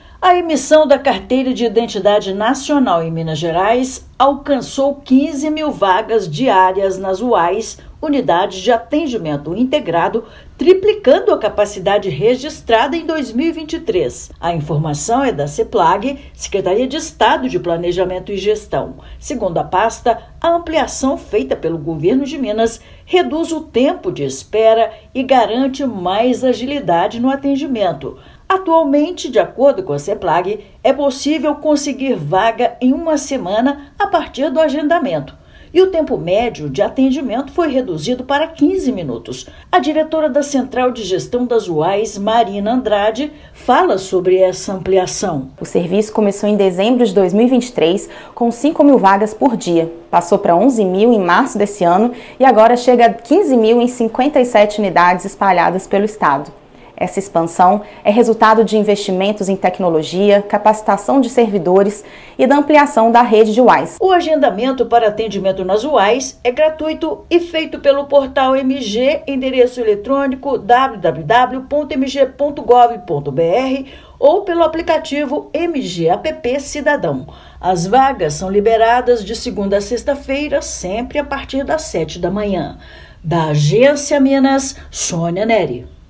Prazo para agendamento e tempo médio de atendimento foram reduzidos; serviço está disponível nas 57 unidades do estado. Ouça matéria de rádio.